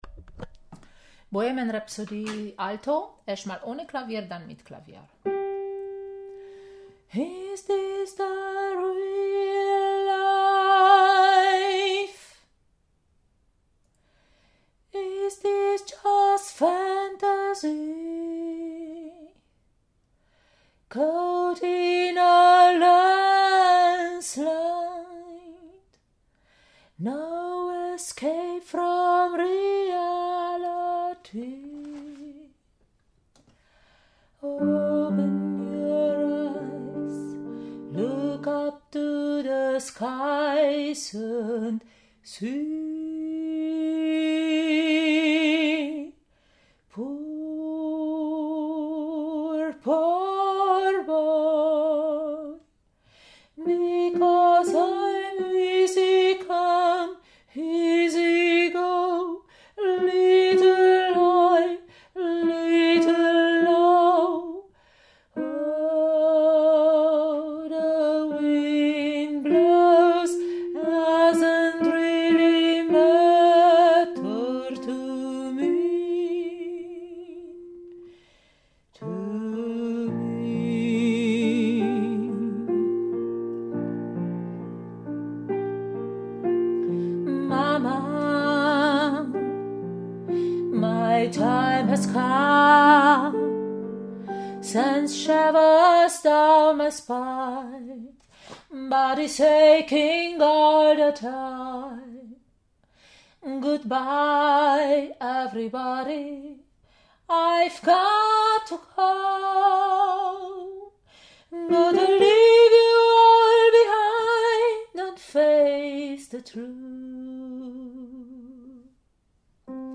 Bohemian Rhapsody – Alto ohne Klavier